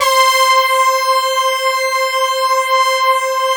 BRASS2 MAT.3.wav